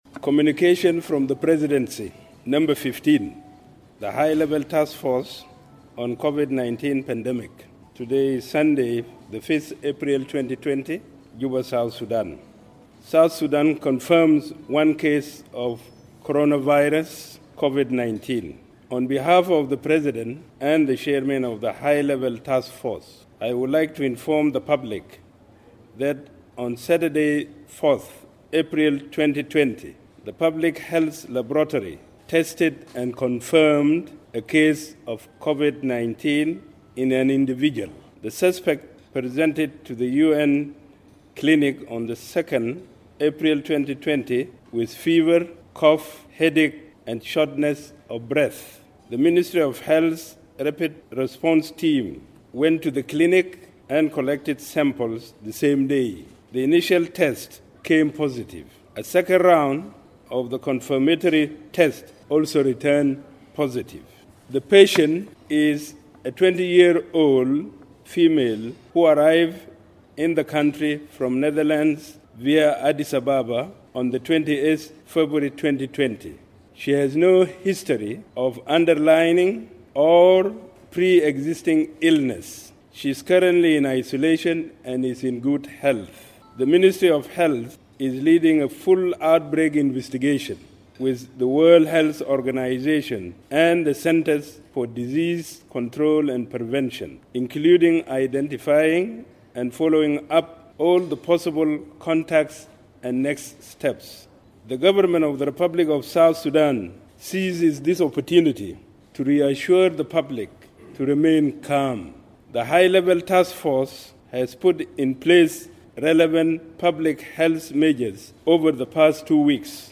Deputy Chairperson of the COVID-19 national task-force First Riek Machar announced the case to journalists in Juba on Sunday morning.